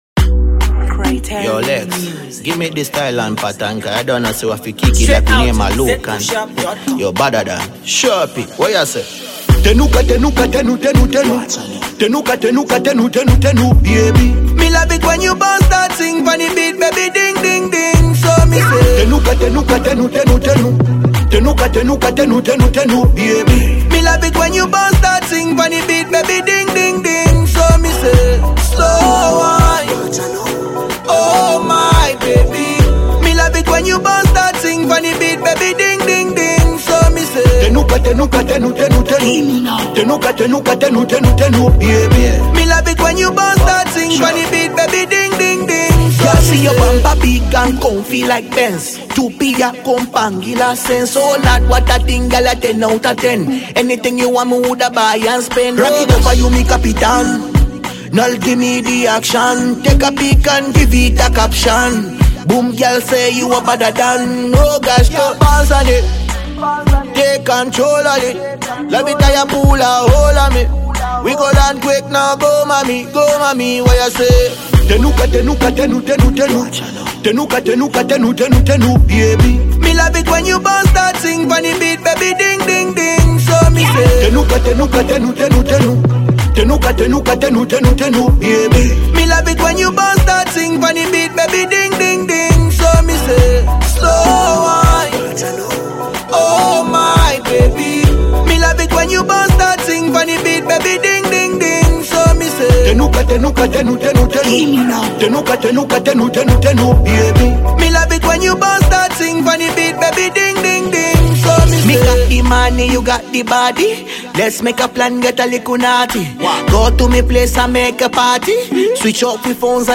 danceable